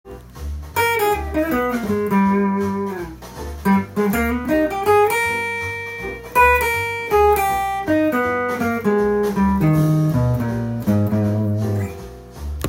Ｇ７のコード上で　半音上のｍＭ７（９）のＡ♭ｍＭ７（９）